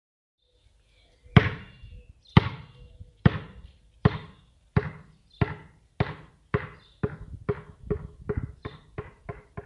Pelota de Basquetbol rebotando: Efectos de sonido negocios
Este efecto de sonido ha sido grabado para capturar la naturalidad del sonido de una pelota de baloncesto impactando el suelo, proporcionando un sonido claro y distintivo que se integrará perfectamente en tus proyectos.
Tipo: sound_effect
Pelota de Basketball rebotando.mp3